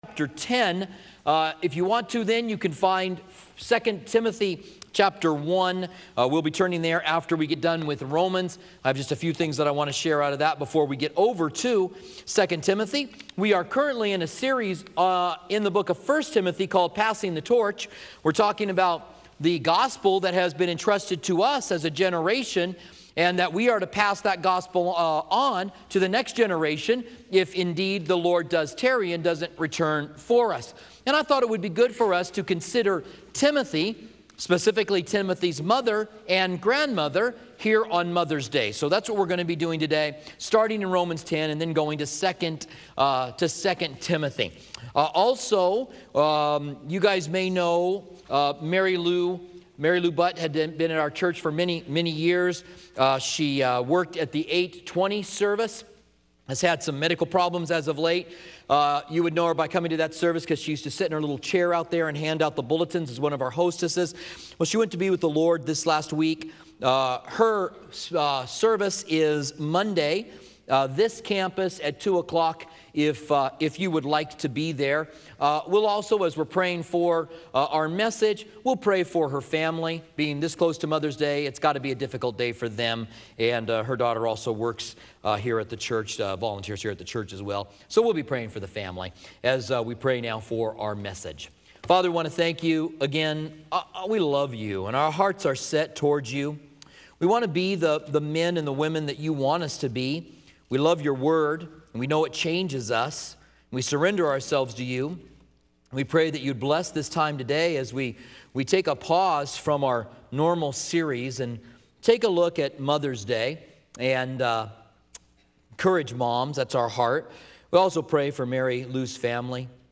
Holiday Message